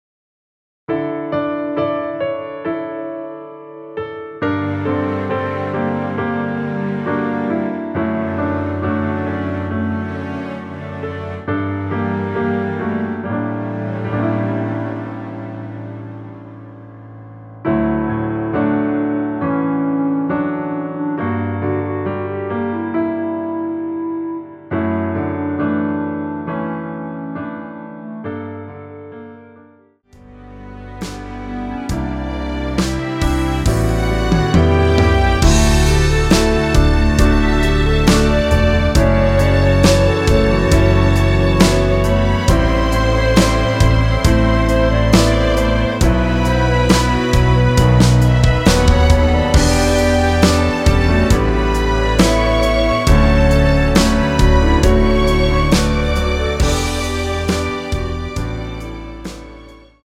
(-3)내린 멜로디 포함된 MR 입니다.(미리듣기 참조)
◈ 곡명 옆 (-1)은 반음 내림, (+1)은 반음 올림 입니다.
앞부분30초, 뒷부분30초씩 편집해서 올려 드리고 있습니다.